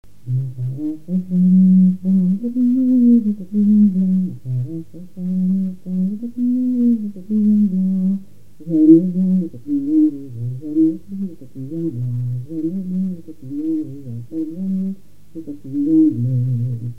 Mémoires et Patrimoines vivants - RaddO est une base de données d'archives iconographiques et sonores.
Localisation Langon (Le)
Genre laisse
Catégorie Pièce musicale inédite